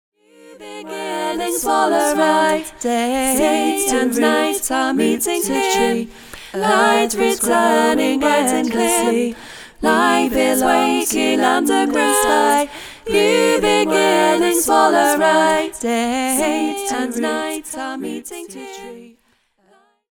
A song for group singing
Parts – 4